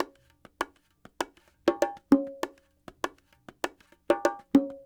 44 Bongo 08.wav